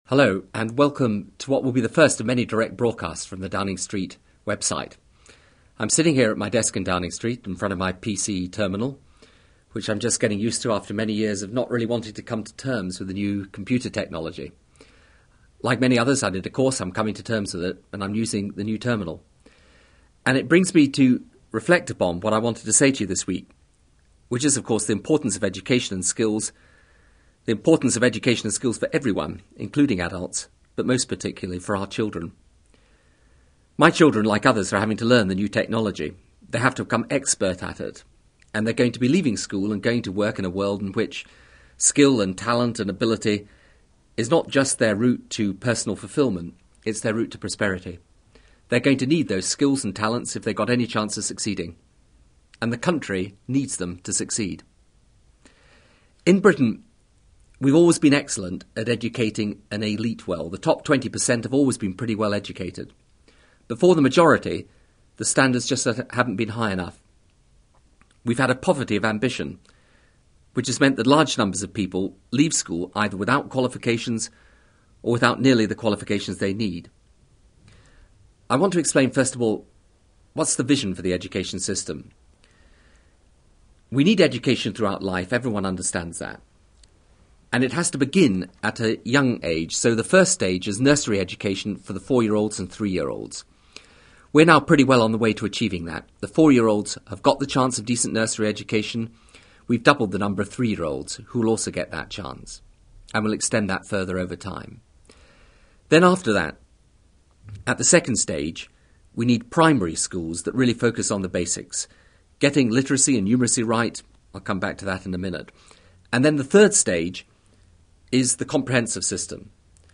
February 11, 2000: British Prime Minister Tony Blair has started a weekly audio broadcast to emulate the weekly US Presidential radio bnroadcasts. It's only on the Internet and the first issue - focussed on education - was a straightforward(if not dull) read.